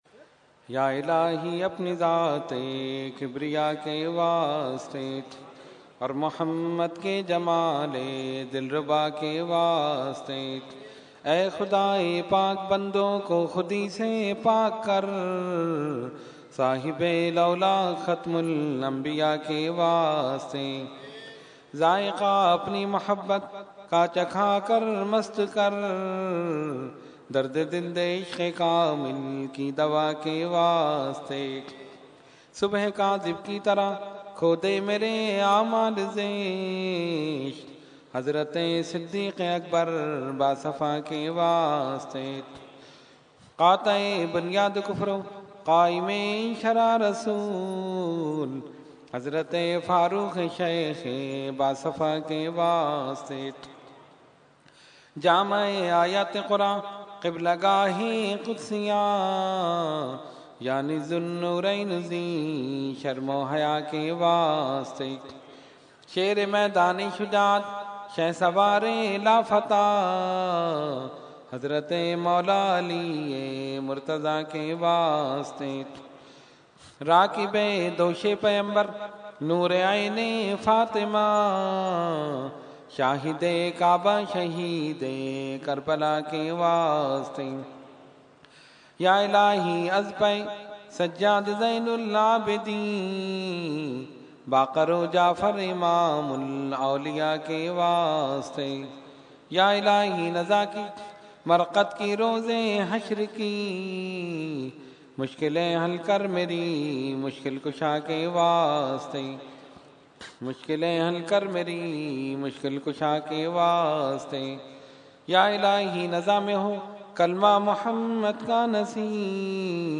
Category : Dua | Language : UrduEvent : Urs Qutbe Rabbani 2015